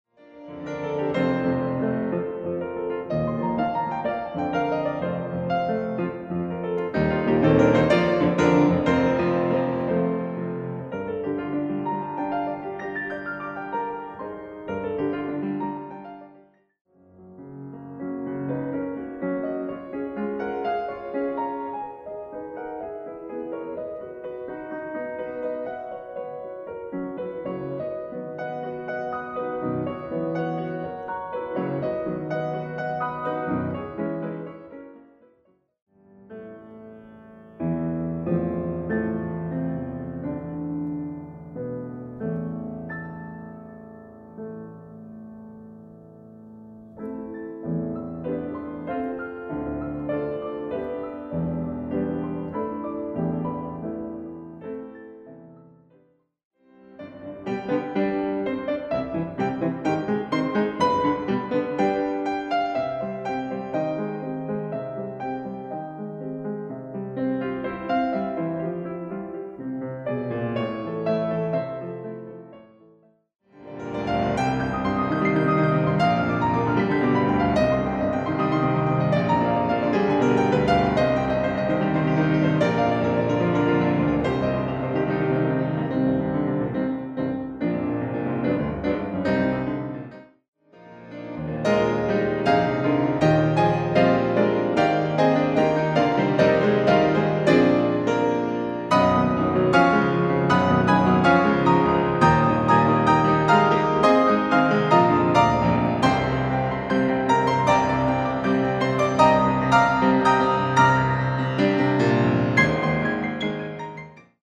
A four-movement collection of solo piano arrangements
Audio preview of all four movements:
is an expression of pure happiness and joy
polyphonic 4-part fugue at the end
Level:  Very advanced